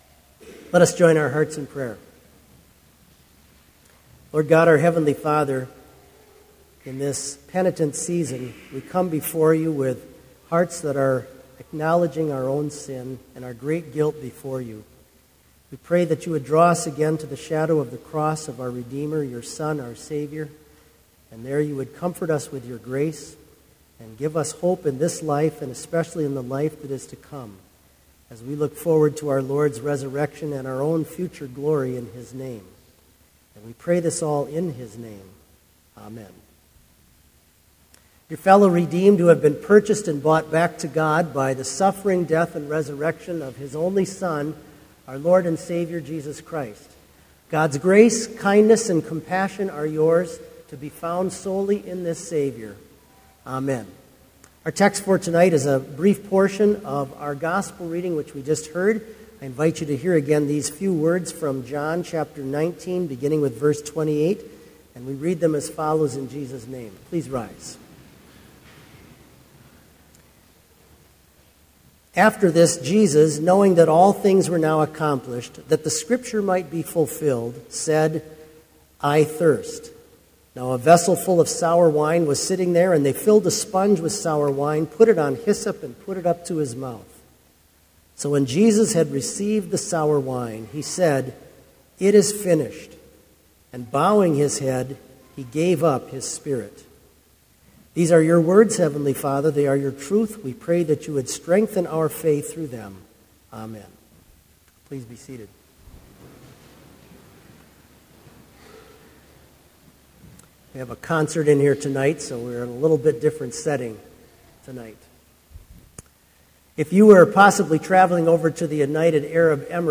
Sermon audio for Lenten Vespers - March 16, 2016